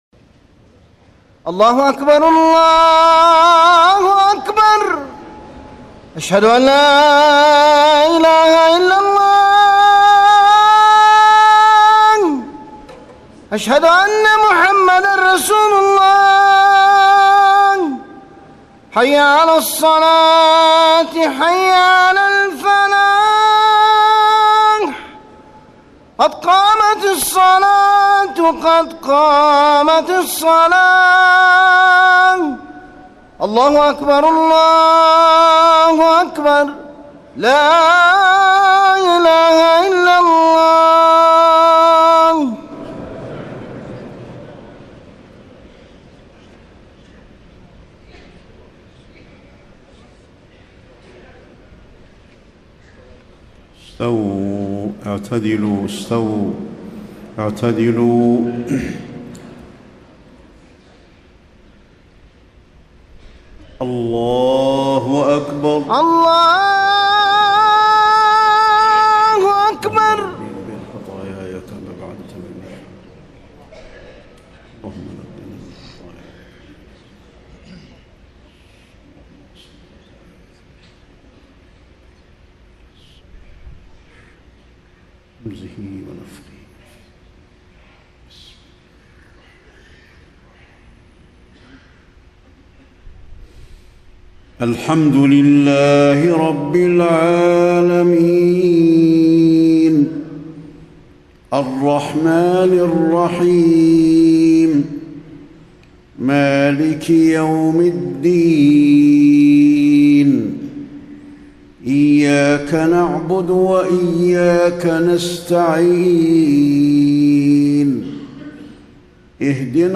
صلاة العشاء 1-5-1434 خواتيم سورة الرحمن > 1434 🕌 > الفروض - تلاوات الحرمين